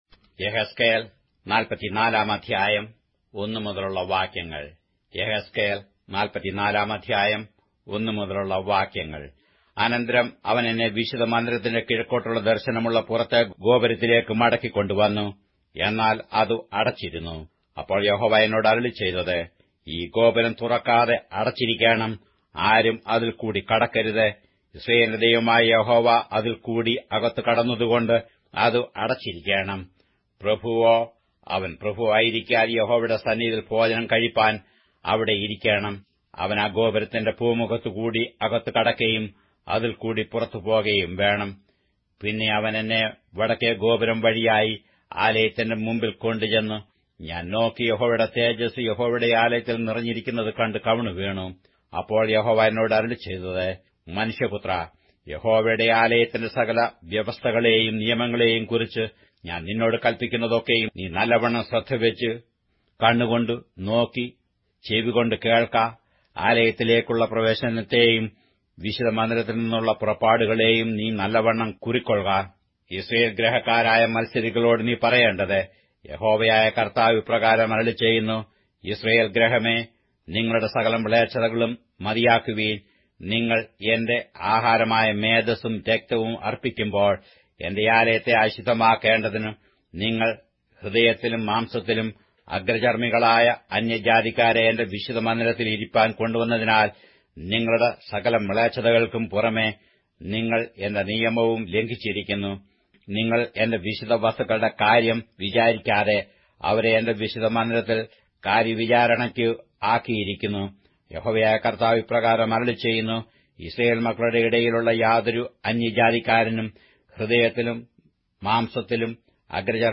Malayalam Audio Bible - Ezekiel 3 in Hcsb bible version